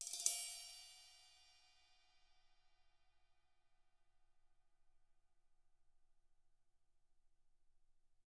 Index of /90_sSampleCDs/ILIO - Double Platinum Drums 2/Partition H/CYMBALRUFFSD